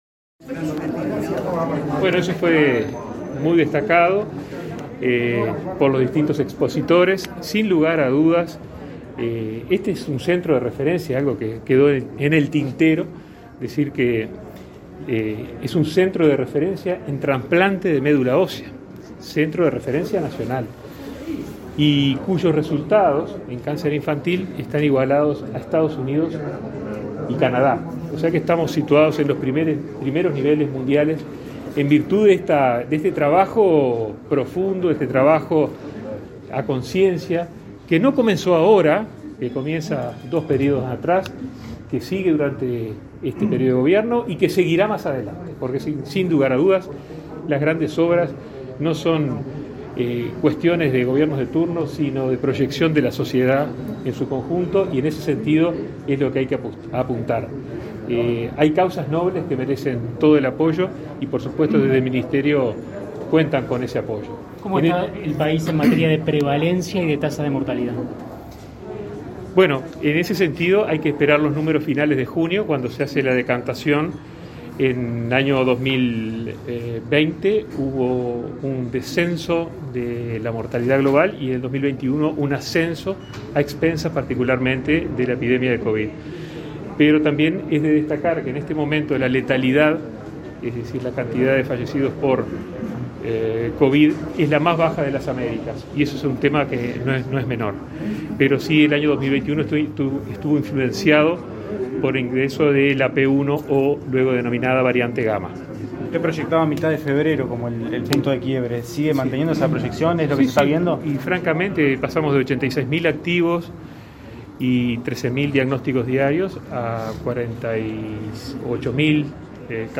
Declaraciones a la prensa del ministro Daniel Salinas
Declaraciones a la prensa del ministro Daniel Salinas 15/02/2022 Compartir Facebook X Copiar enlace WhatsApp LinkedIn El ministro de Salud Pública, Daniel Salinas, participó este martes 15 en la inauguración de obras en la fundación Pérez Scremini y, luego, dialogó con la prensa.